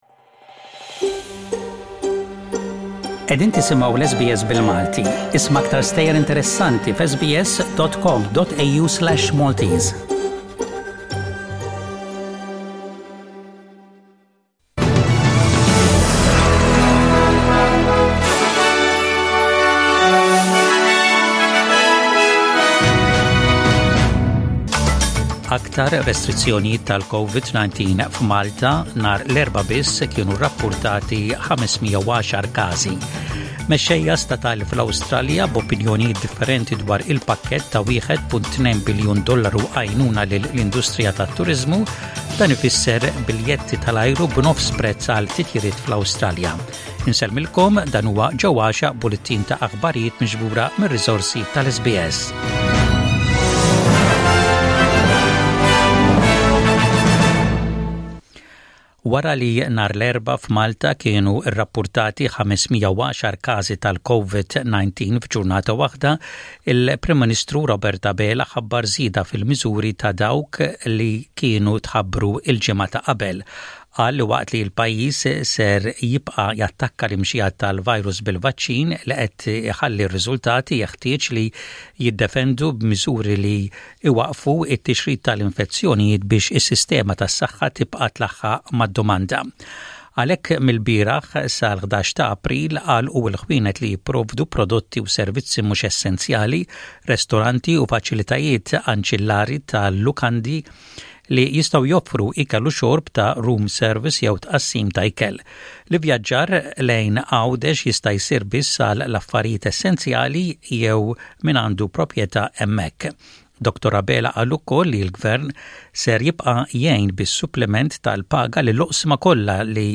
SBS Radio | Maltese News: 12/03/21